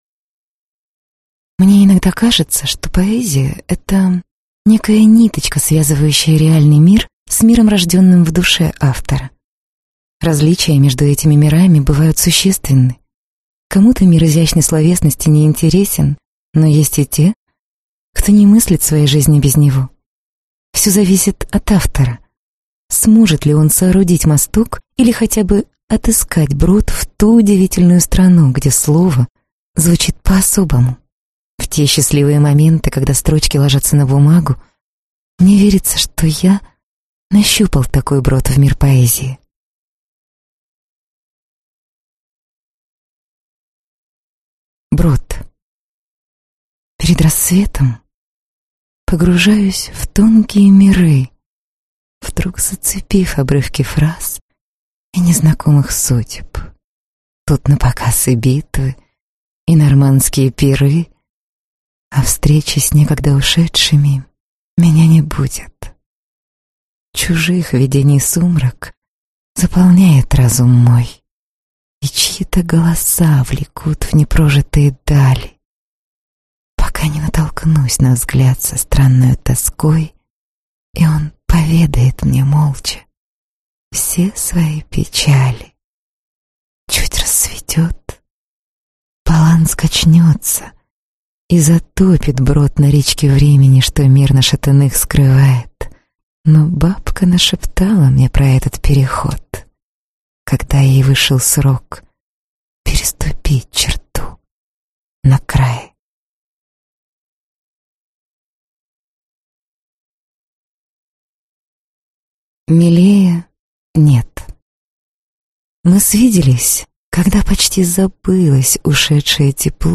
Аудиокнига Брод (сборник) | Библиотека аудиокниг